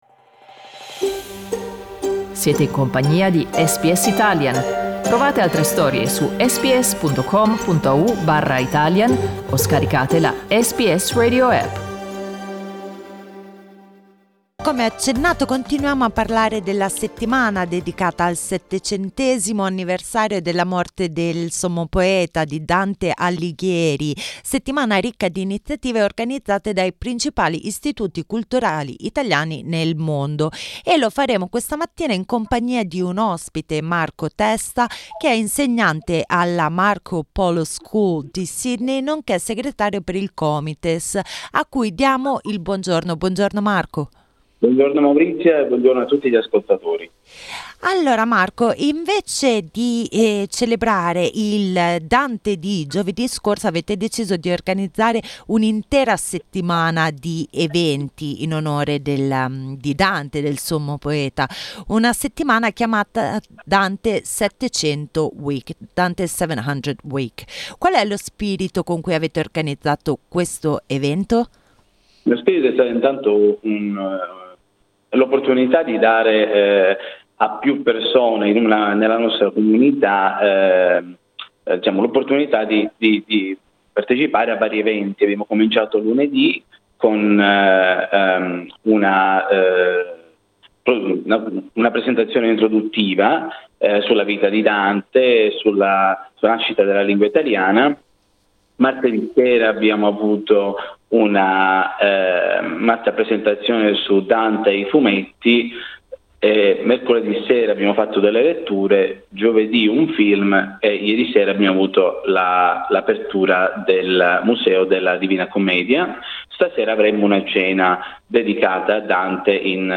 Ascolta l'intervista: LISTEN TO A cena con Dante e altri eventi SBS Italian 13:47 Italian Maggiori informazioni sono disponibili sul sito del CNA NSW .